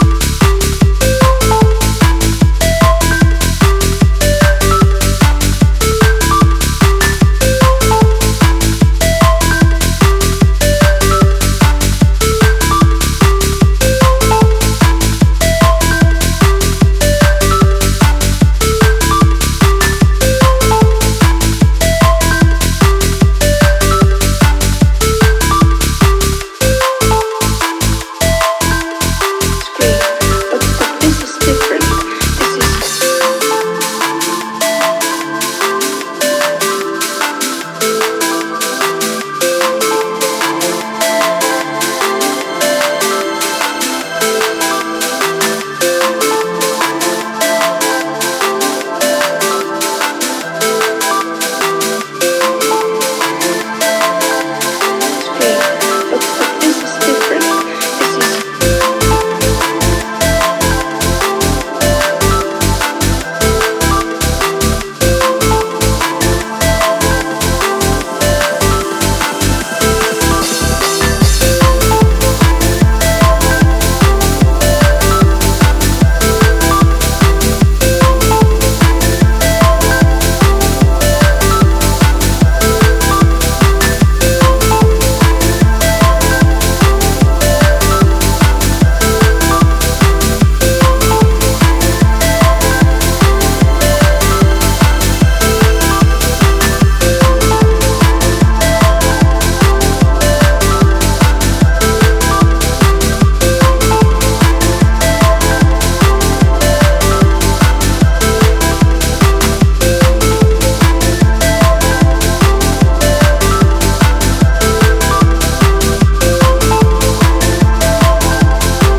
Electro Techno Trance